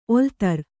♪ oltar